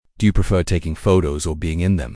Part 1 (Introduction & Interview)